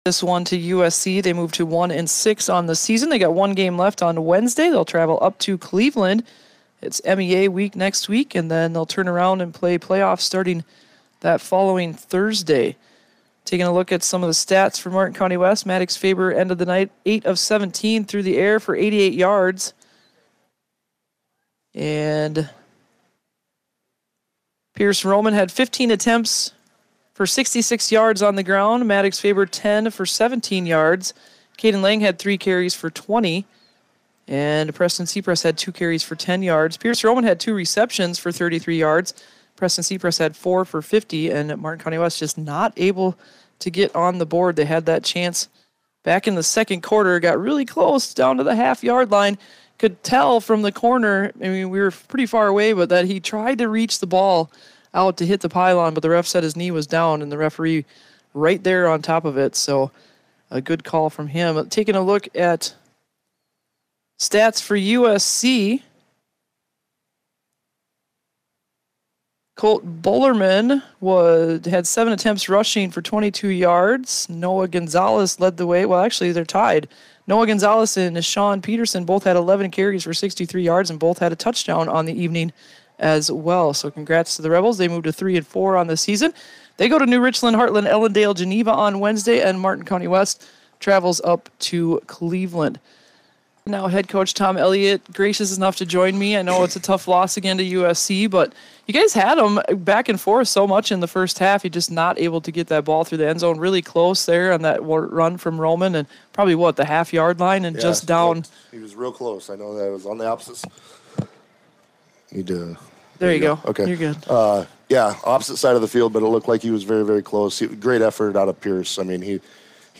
POSTGAME SHOW
10-9-25-mcwfb-vs-usc-post-game-show.mp3